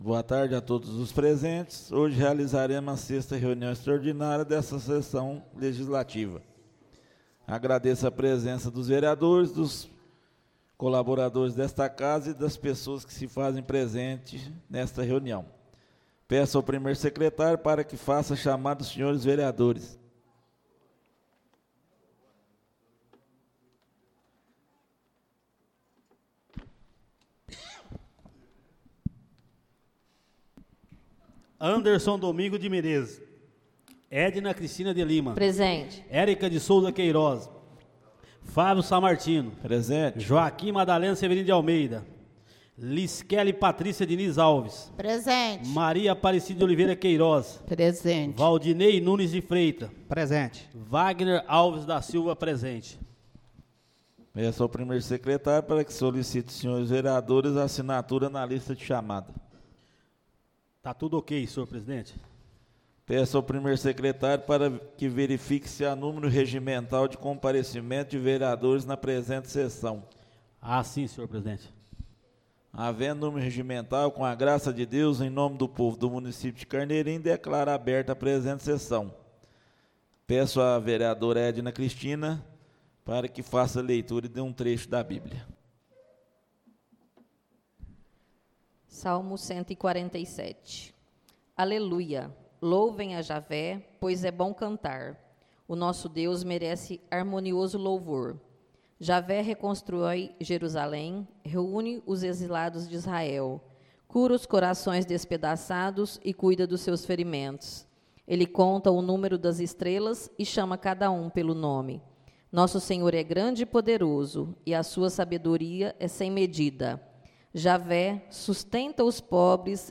Áudio da 06.ª reunião extraordinária de 2025, realizada no dia 8 de Abril de 2025, na sala de sessões da Câmara Municipal de Carneirinho, Estado de Minas Gerais.